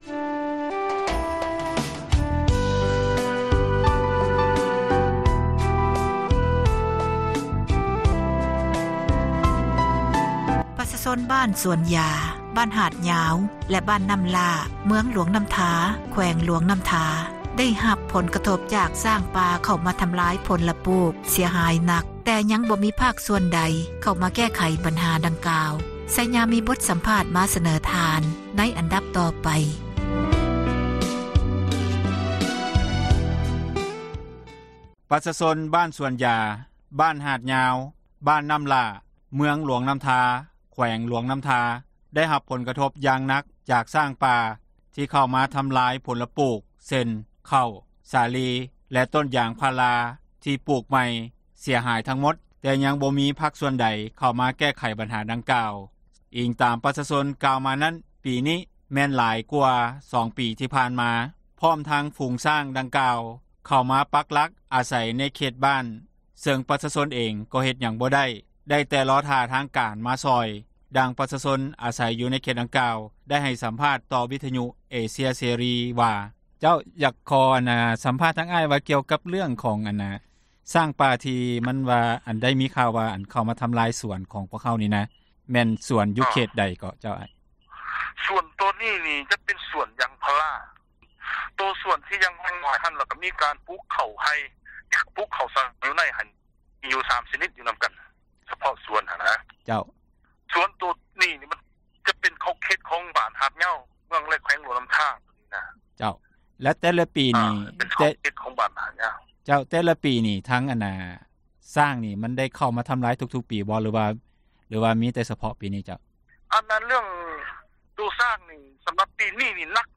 ປະຊາຊົນທີ່ອາສັຍຢູ່ໃນເຂດ ດັ່ງກ່າວ ໄດ້ໃຫ້ສັມພາດ ຕໍ່ວິທຍຸ ເອເຊັຽ ເສຣີ ດັ່ງຕໍ່ໄປນີ້ວ່າ ...